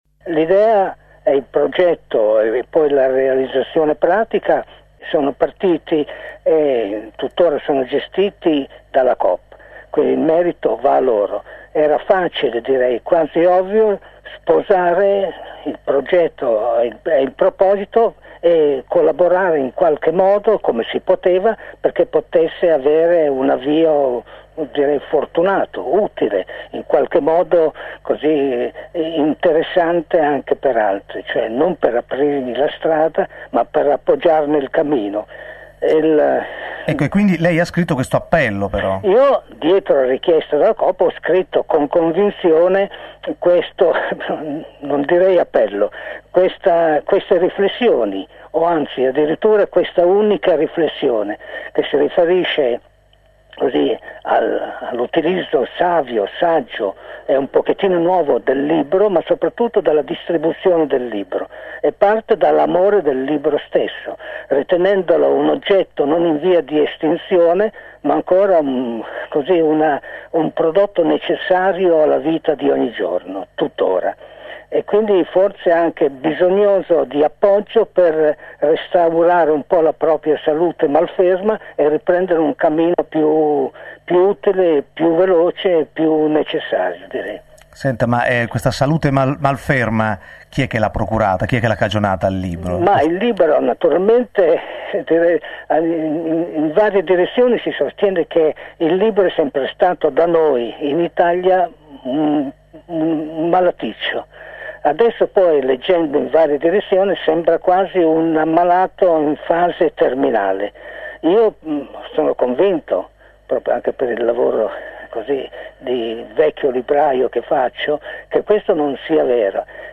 Ascolta l’intervista RoversiOttobre2001